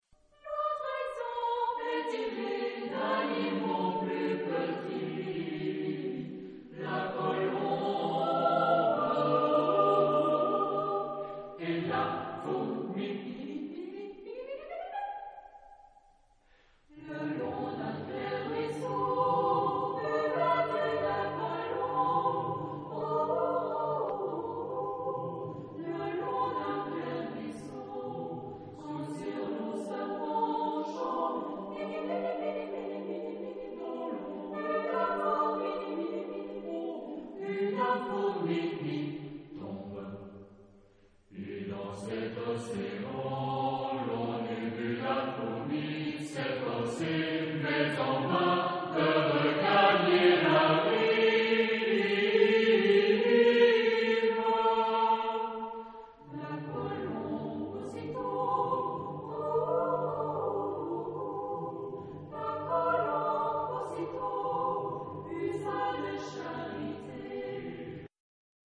Pour grand choeur et petit choeur mixtes a cappella ...
Género/Estilo/Forma: Poema ; Profano
Tipo de formación coral: SATB  (4 voces Coro mixto )
Tonalidad : re mayor ; la menor